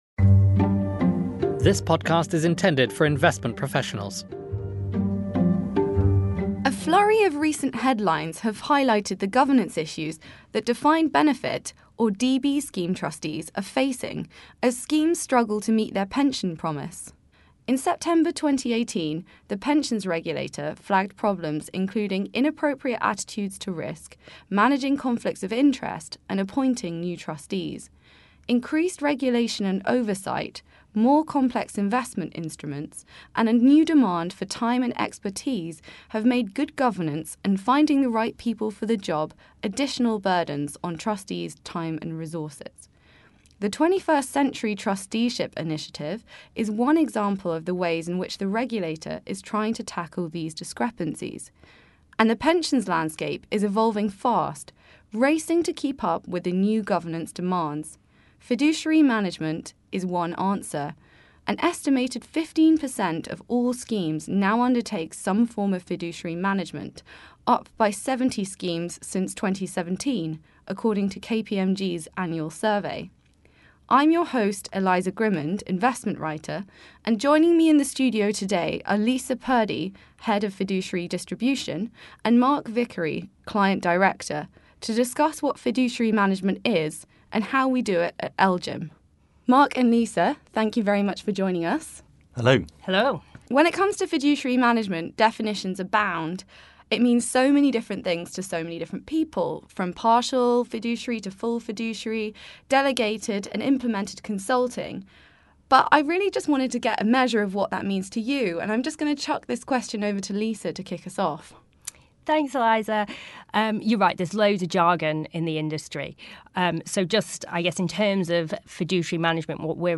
In this conversation, we discuss the key ways defined benefit schemes can improve their effectiveness.